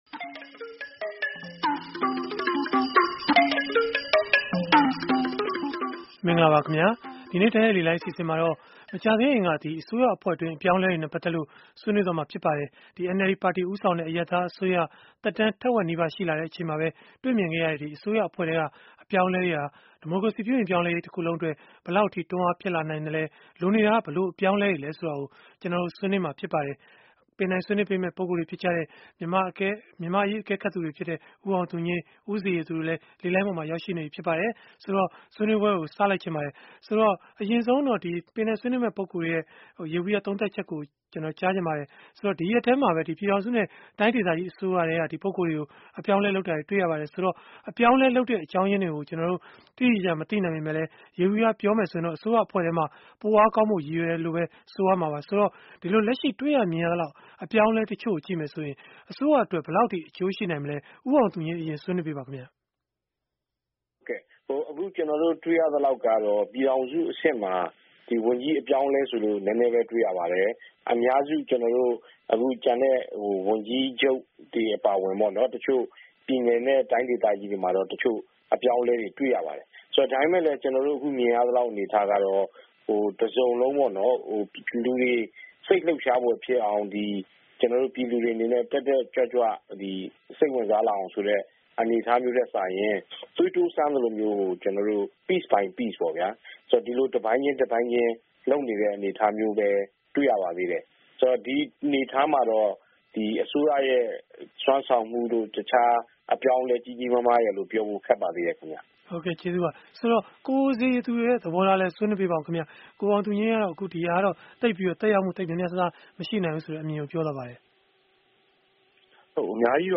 စနေနေ့ည တိုက်ရိုက်လေလှိုင်း အစီအစဉ်မှာ